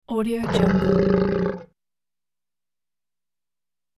Short Menacing Lion Botão de Som